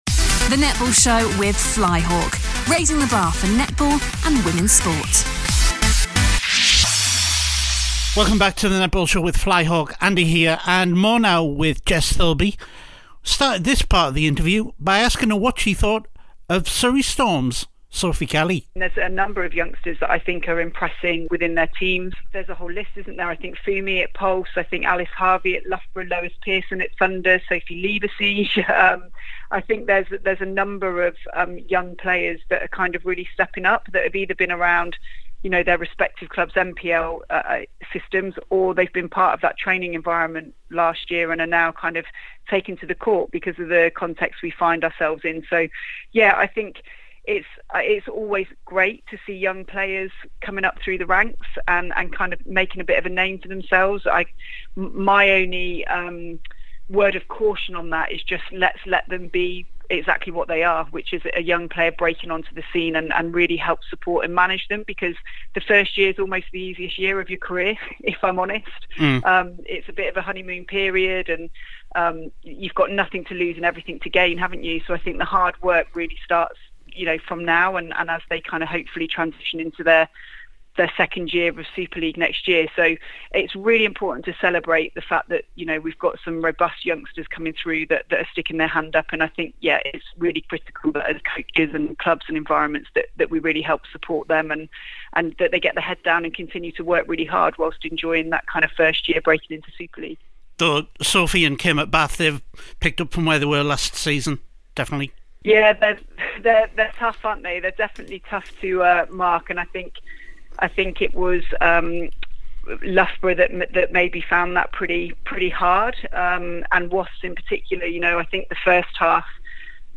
PART ONE : Jess Thirlby Sky Sports Postmatch interviews are used with their permission SKY SPORTS LIVE NETBALL GAME FIXTURE LIST T he Netball Show partnered with Flyhawk